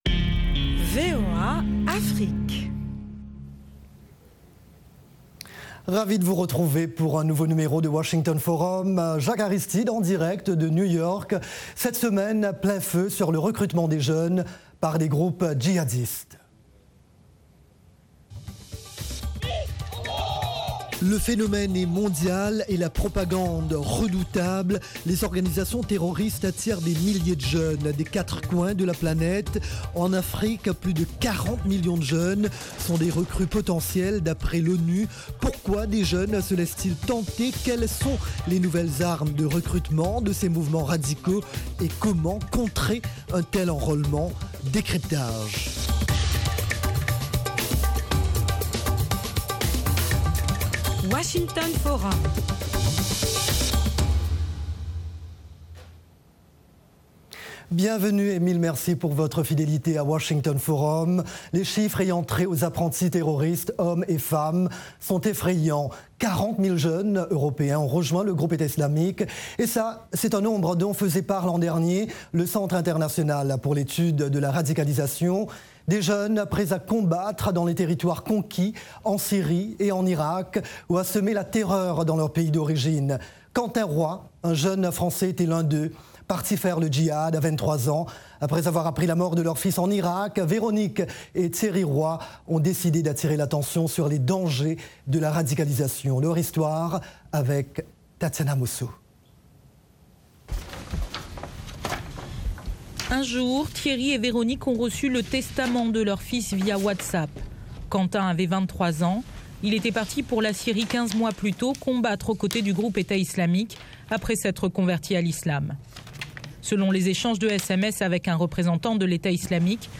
Washington Forum : 30 minutes d'actualité africaine, américaine et internationale. Economie, politique, santé, religion, sports, science, multimédias: nos experts répondent à vos questions en direct, via des Live Remote, Skype, et par téléphone de Dakar à Johannesburg, en passant par le Caire, New York, Paris et Londres. Cette émission est diffusée en direct par satellite à l’intention des stations de télévision et radio partenaires de la VOA en Afrique francophone.